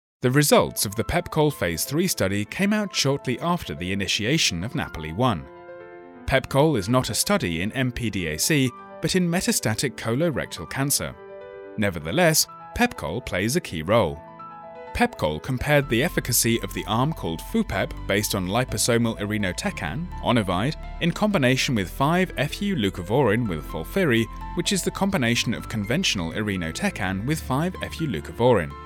Embauchez des acteurs vocaux de narration médicale pour votre projet
Anglais (britannique)
Authentique
Amical
Sophistiqué